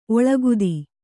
♪ oḷagudi